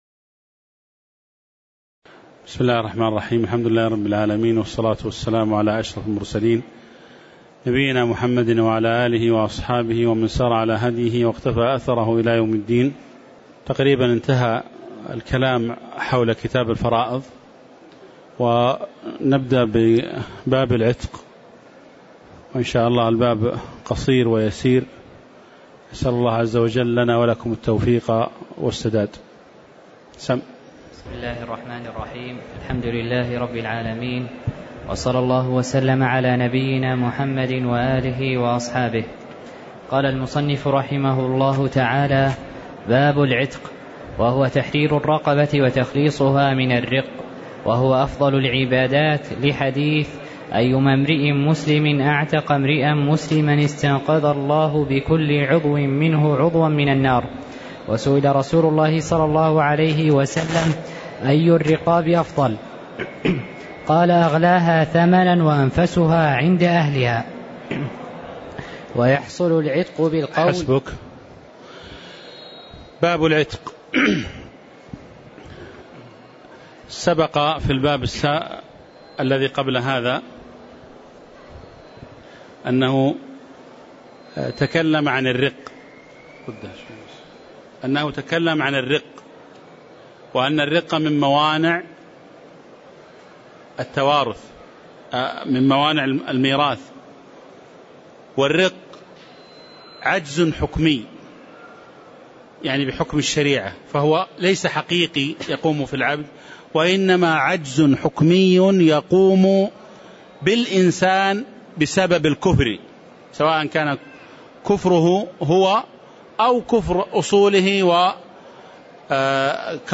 تاريخ النشر ٢٣ شوال ١٤٣٧ هـ المكان: المسجد النبوي الشيخ